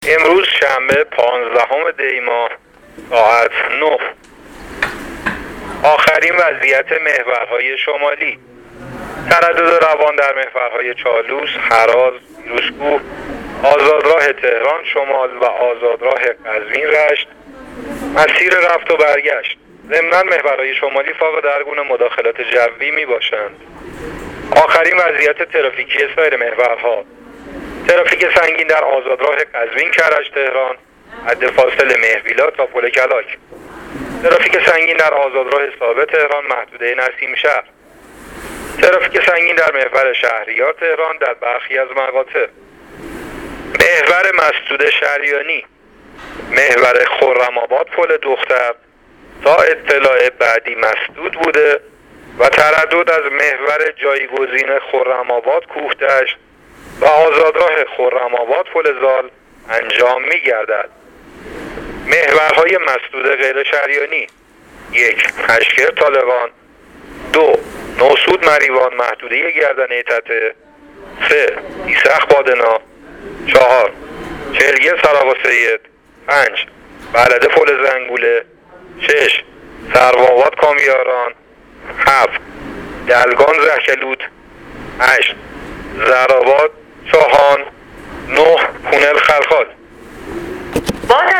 گزارش رادیو اینترنتی از آخرین وضعیت ترافیکی جاده‌ها تا ساعت ۹ پانزدهم دی؛